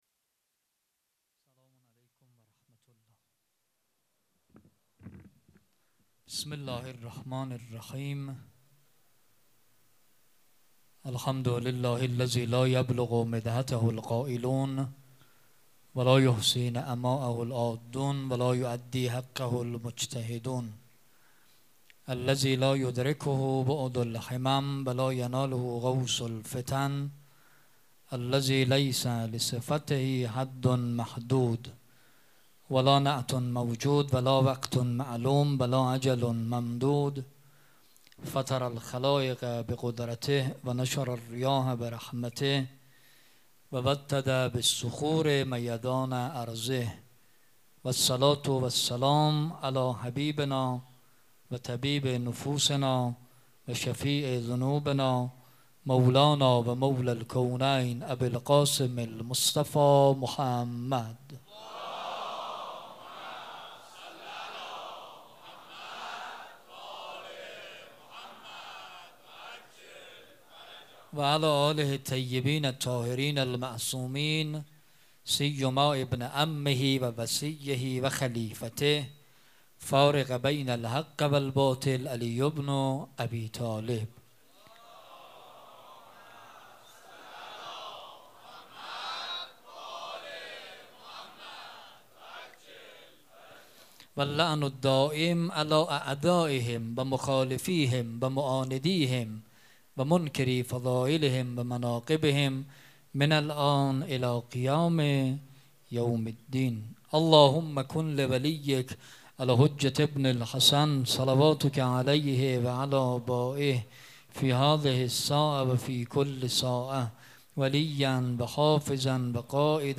سخنرانی
شب چهارم محرم